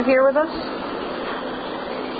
That seems to be the question I was asked when I did an EVP session at a nursing home.